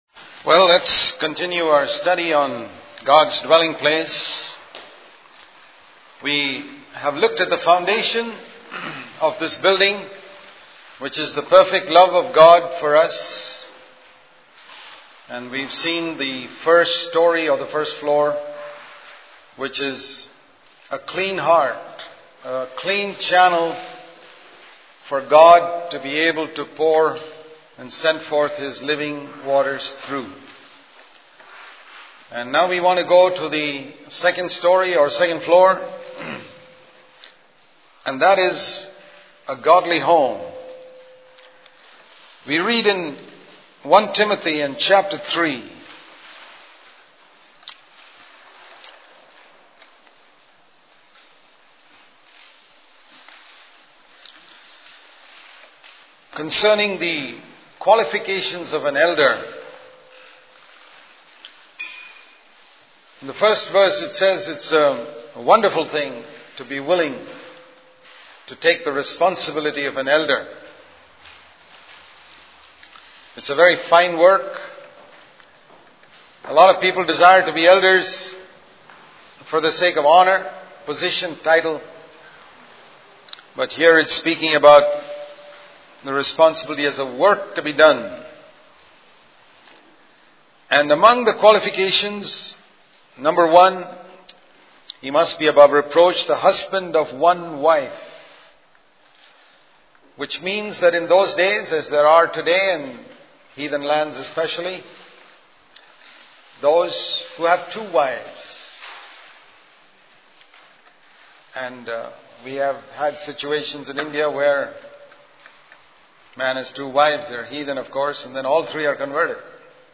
In this sermon, the speaker emphasizes the importance of family and its role in building God's house. He criticizes churches that operate like organizations with a CEO, rather than functioning as a family. The speaker highlights the responsibility of parents, particularly fathers, in training and disciplining their children.